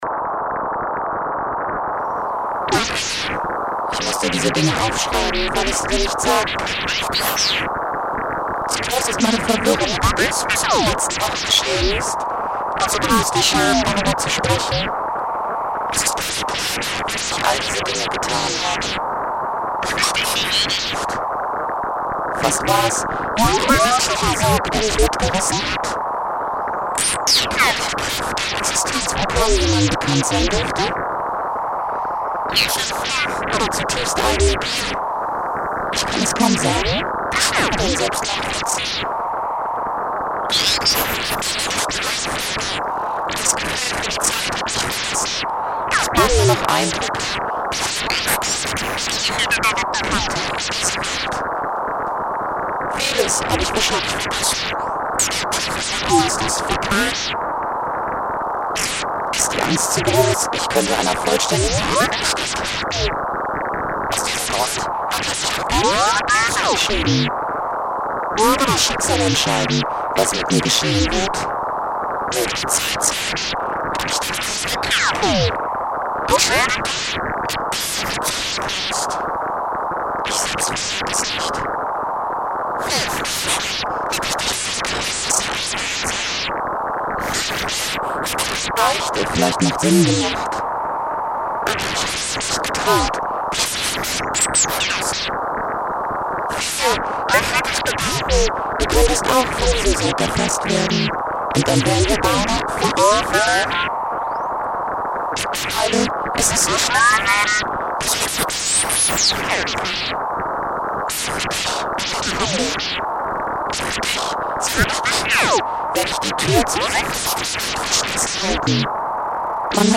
Installation im Rahmen der Langen Nacht der Kölner Museen
Über zwei Boxen in den Ecken ist eine verzerrte Stimme zu hören.